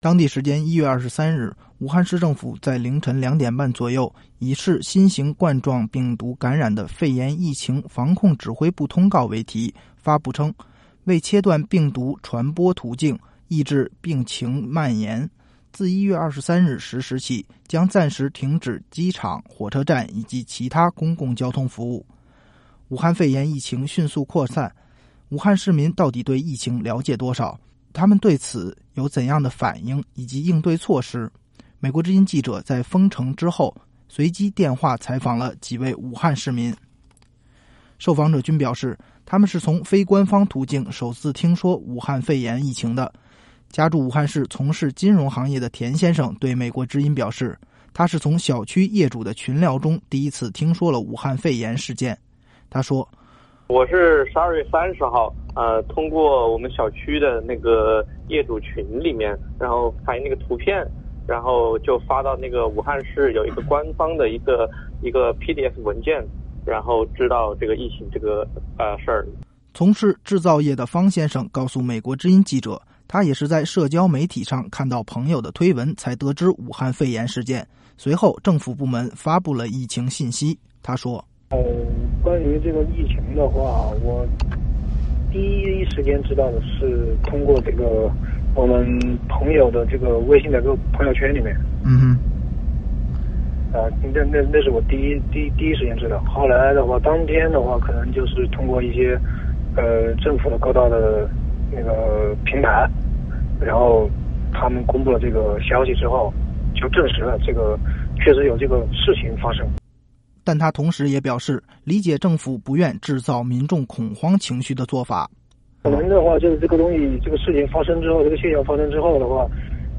美国之音记者在“封城”之后随机电话采访了几位武汉市民。